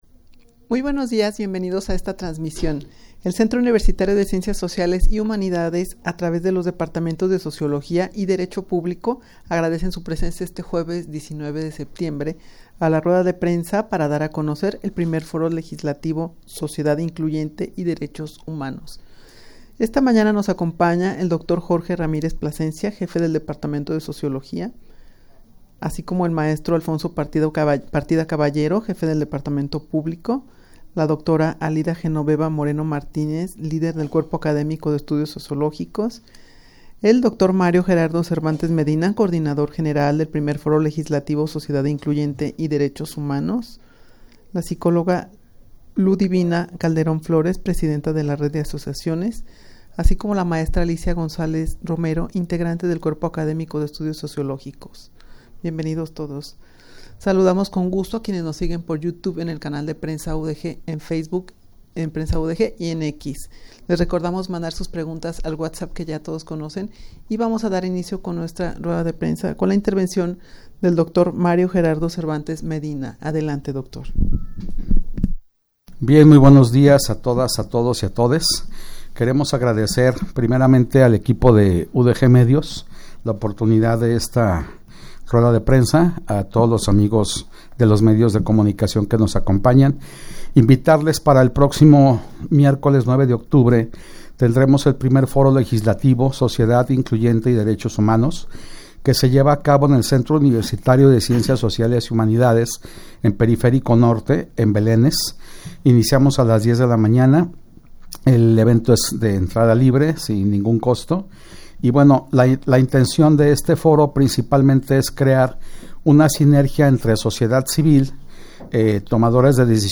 Audio de la Rueda de Prensa
rueda-de-prensa-para-dar-a-conocer-el-i-foro-legislativo-sociedad-incluyente-y-derechos-humanos.mp3